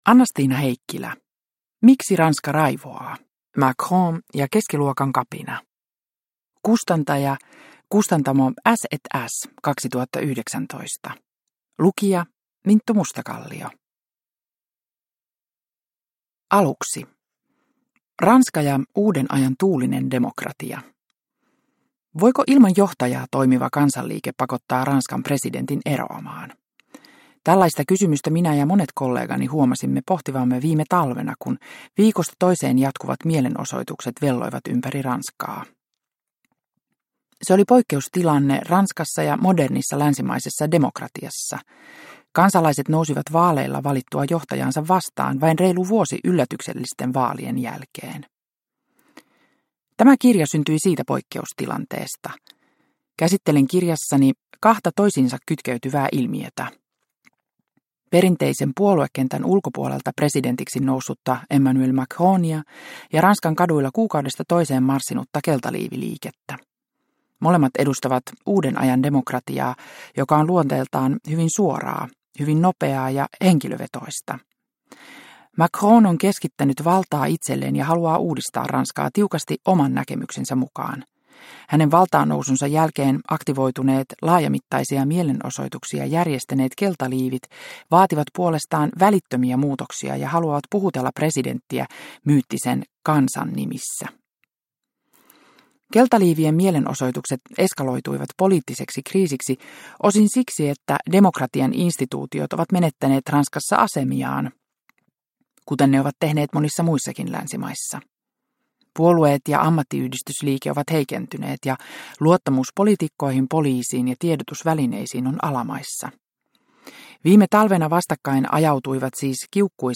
Miksi Ranska raivoaa? – Ljudbok – Laddas ner